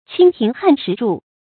蜻蜓撼石柱 qīng tíng hàn shí zhù
蜻蜓撼石柱发音